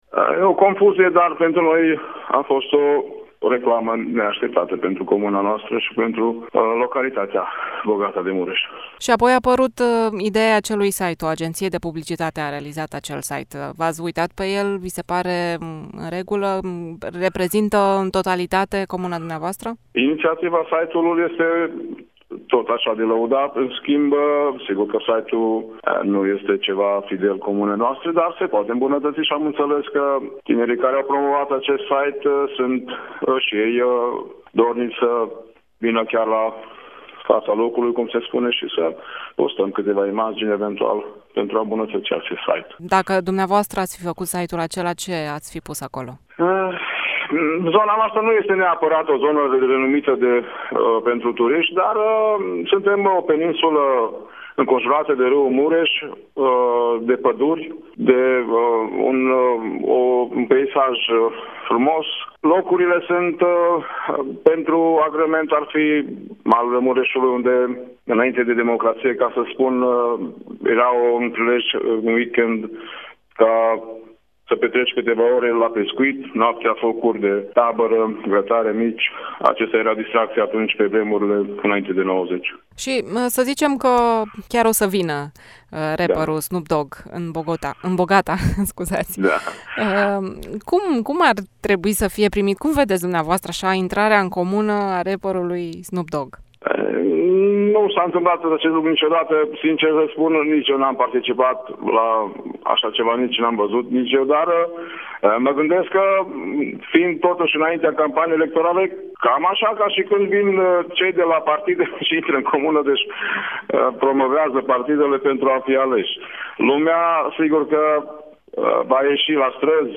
Primarul-comunei-Bogata-Laszlo-Bartha.mp3